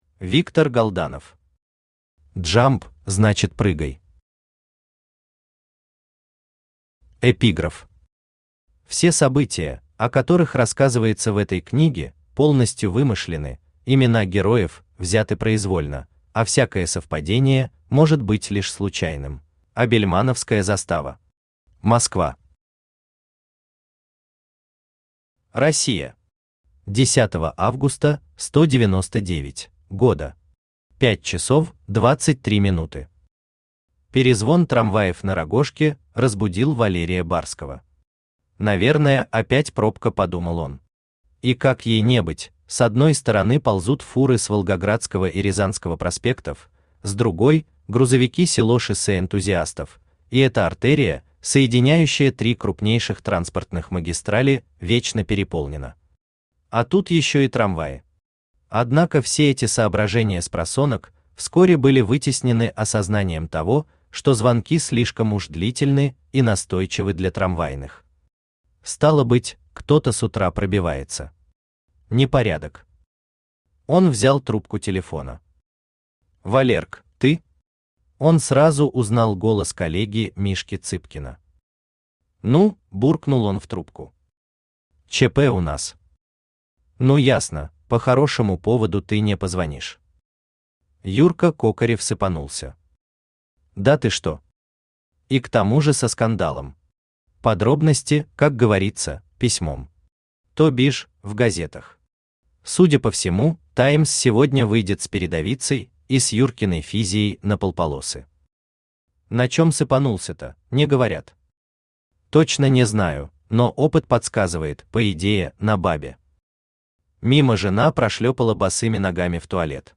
Автор Виктор Галданов Читает аудиокнигу Авточтец ЛитРес.